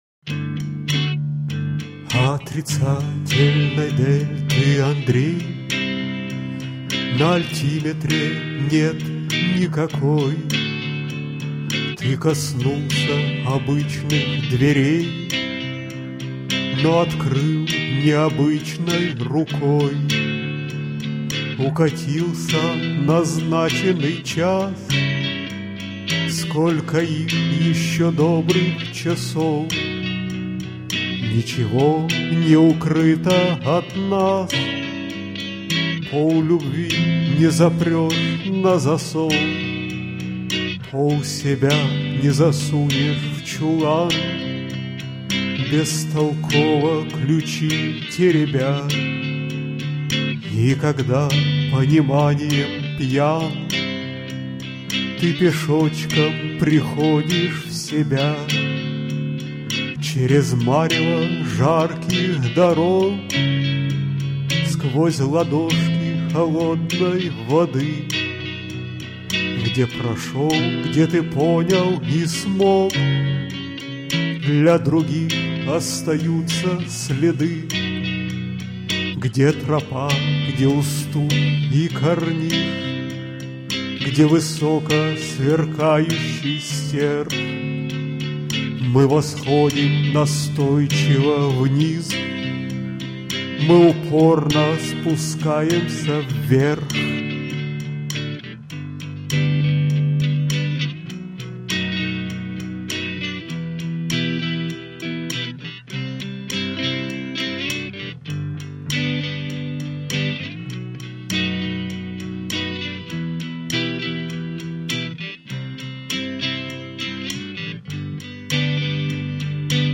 Да, чтоб два раза не вставать: записал новую версию последней песни, там увеличен на треть темп (с 150 до 200 bpm) и выше на малую терцию исполнение (в G-dur/E-moll вместо E-dur/Cis-moll) --
v1, MP3 64 kbps mono, 1094kb, и предыдущий вариант для сравнения v0, MP3 64 kbps mono, 1360kb.
Дополнительным бонусом ухода от открытой позиции получились аккордовые слайды, аж самому понравилось -- я их в две гитары прописывал, леспол (оба хамбакера) и страт (нековый сингл), через один и тот же аппарат (Yerasov GT-2 + Fender Frontman 15 + Shure SM57). Что удивительно, леспол лучше читается, не ожидал такого на чистом звуке.
Так именно для того, чтоб хоть что-то менялось, у меня переход в параллельный минор на чётных катренах :) Послушал ещё раз -- ну не знаю, нескладуха с разрывом пока, буду ещё думать.